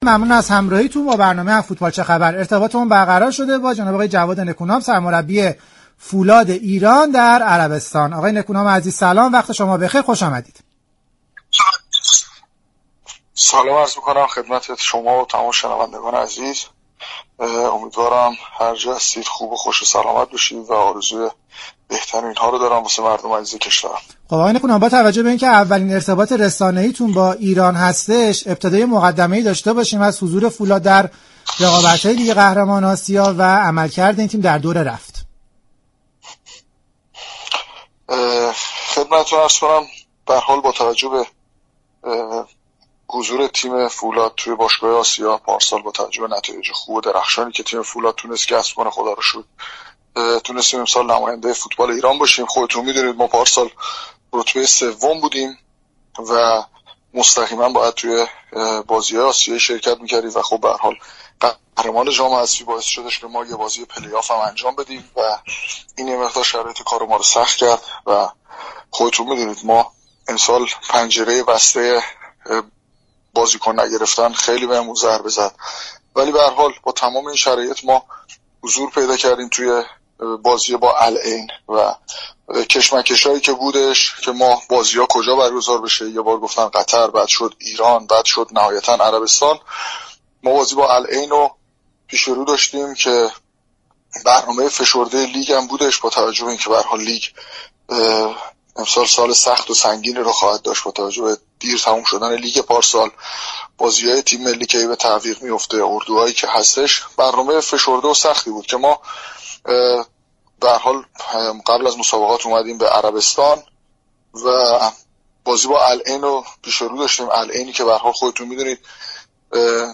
جواد نكونام در گفتگو با برنامه «از فوتبال چه خبر» چهارشنبه 1 اردیبهشت به گفتگو درباره شرایط فولاد در پایان دور رفت مرحله گروهی پرداخت.